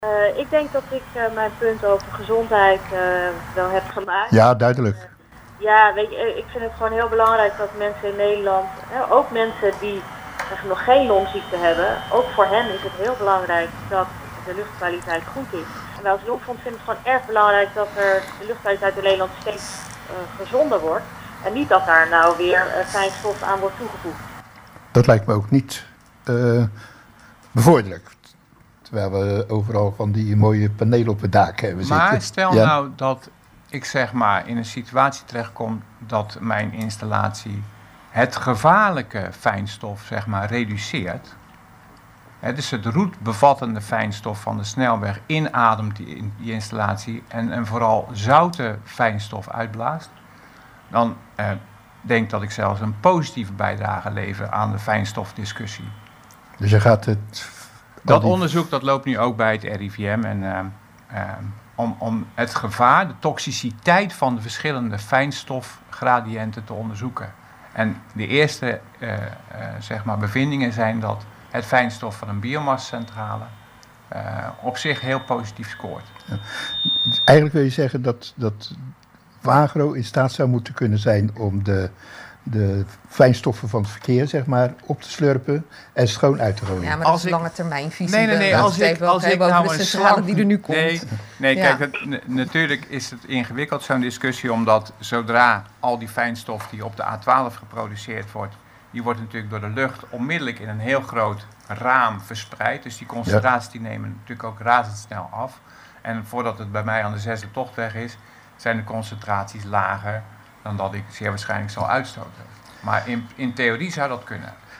Interview bij RTW over biomassacentrales